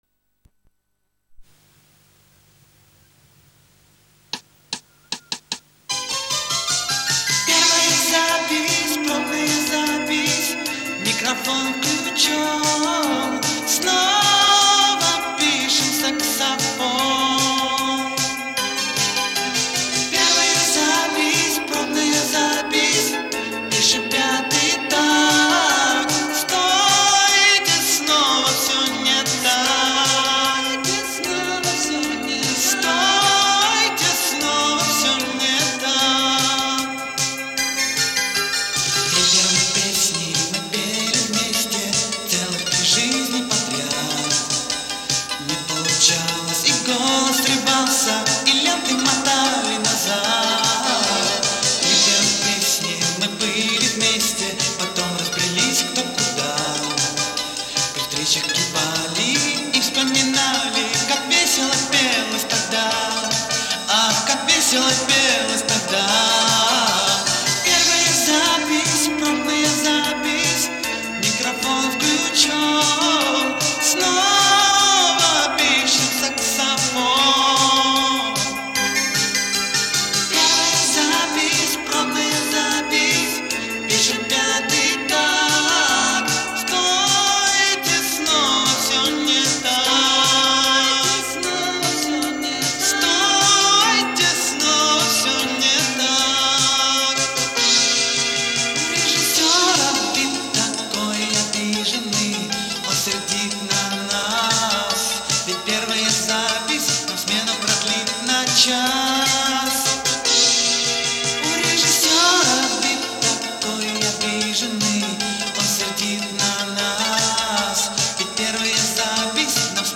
На касете звучит намного лучше, чем оцифровка.